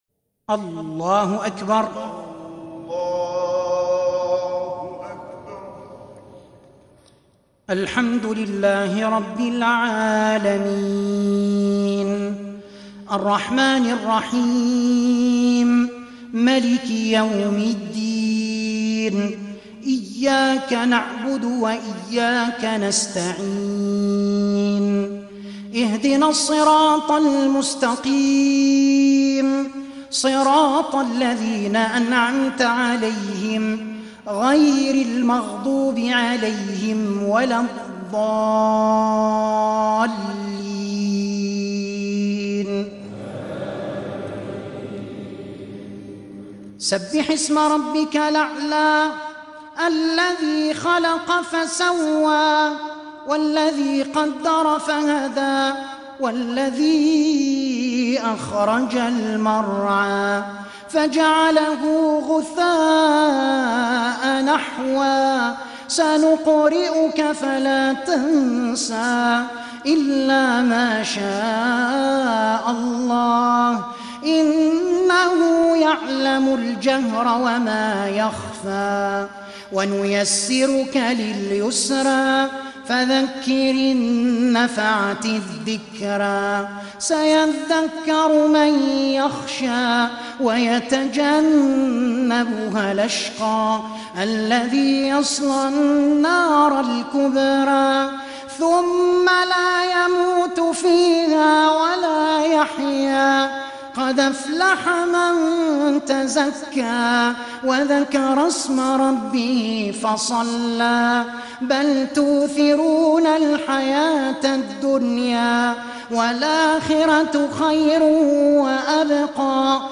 ترتيل جديد!
بأداء حزين - مسجد الأمير عبدالقادر 1443هـ